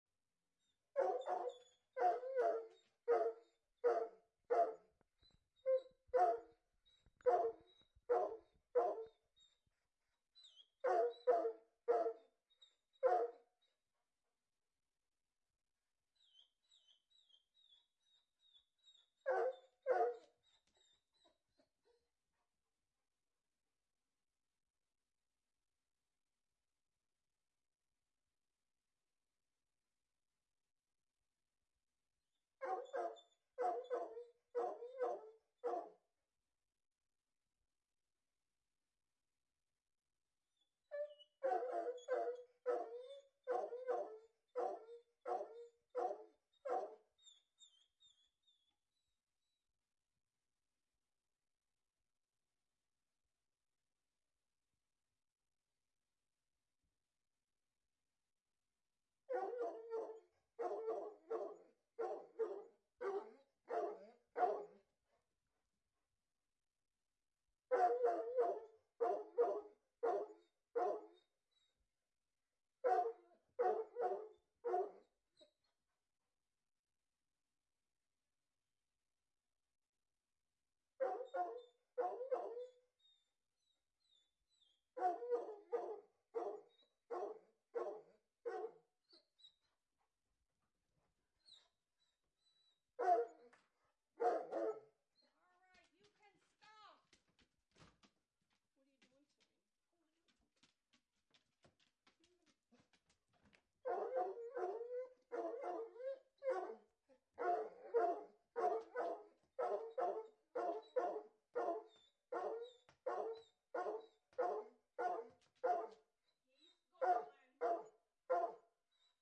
Dog barking 2 Soundboard: Play Instant Sound Effect Button